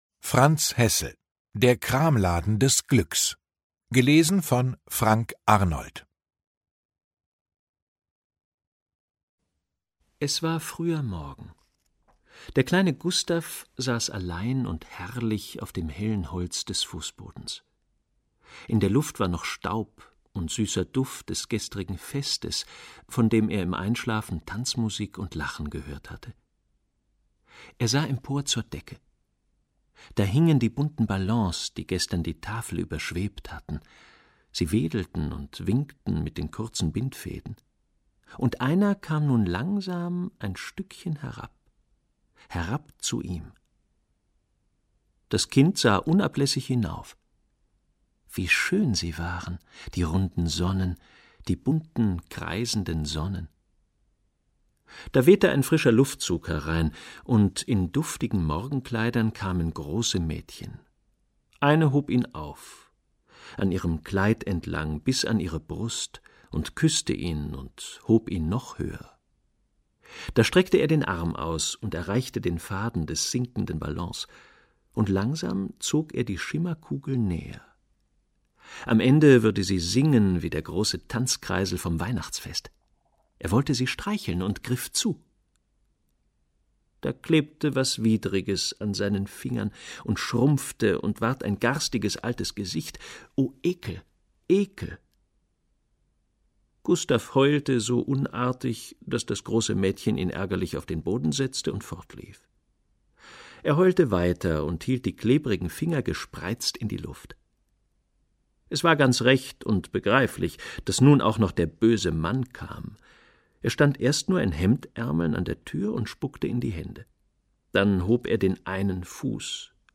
2021 | Ungekürzte Lesung